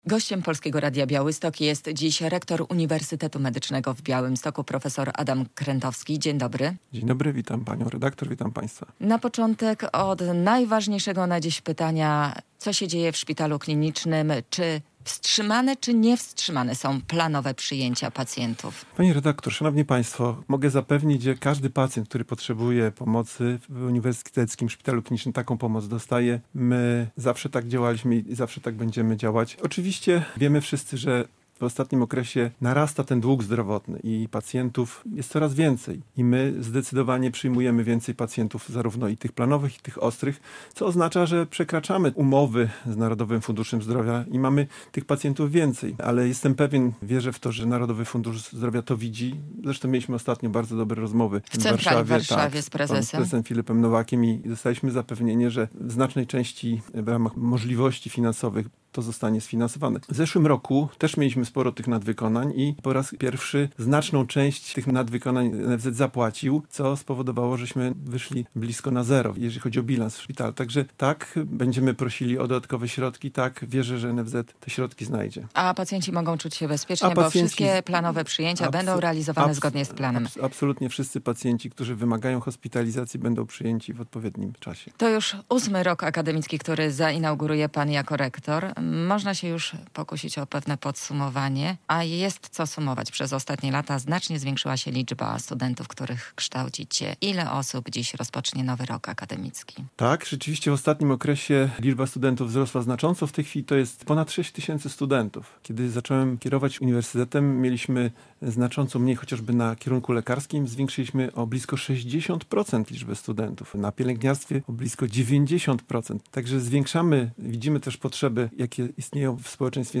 Gość